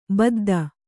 ♪ badda